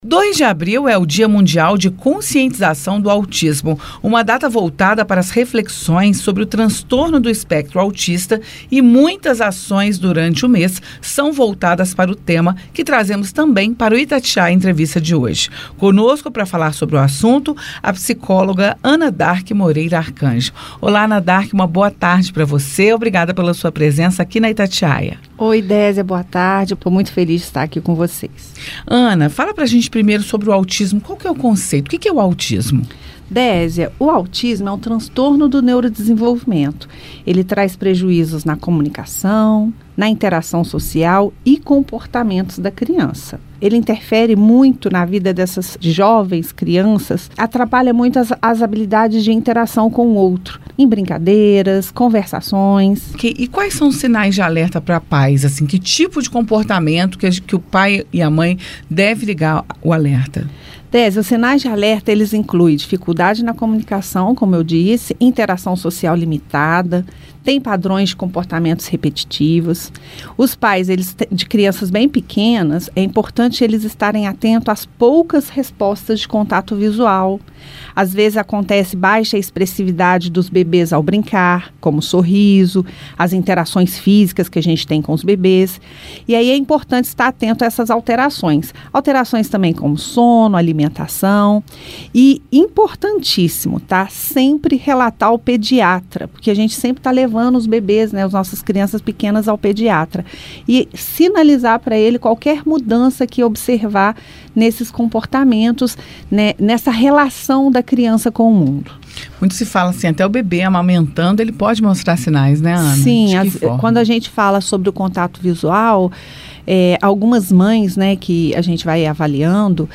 Dia Mundial do Autismo: especialista fala sobre o transtorno e como pais podem perceber os sinais - Rádio FM Itatiaia